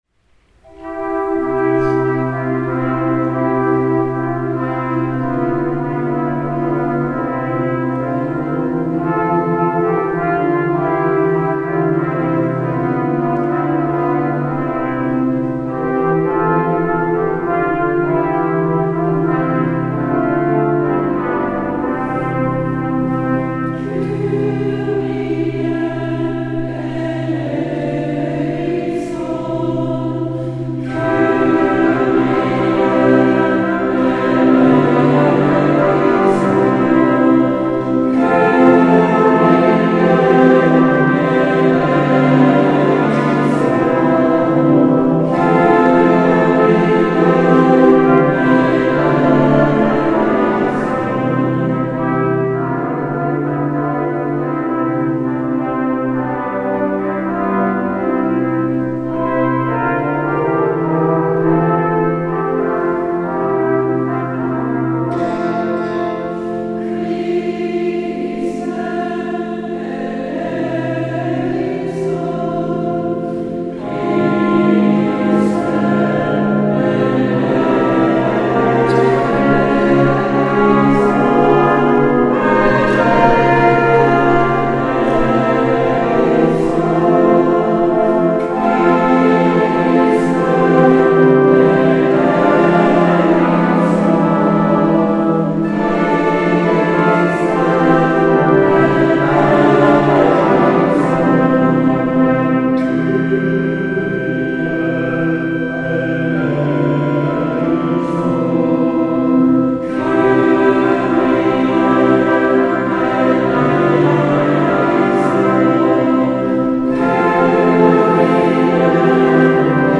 Gattung: Messe